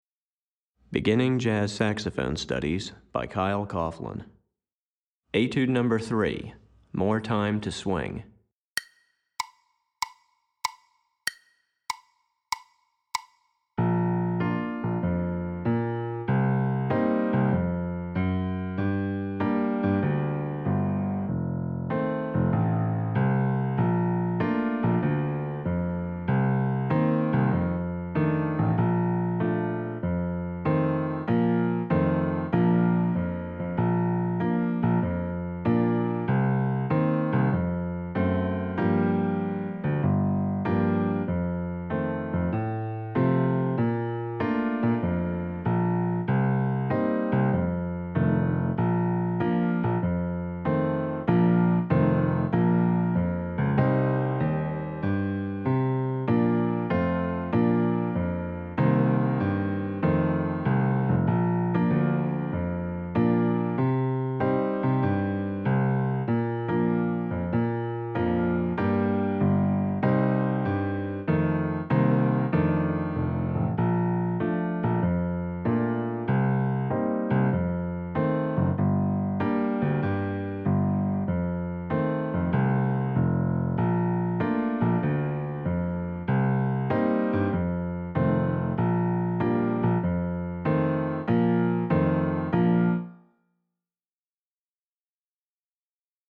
piano
Piano Accompaniment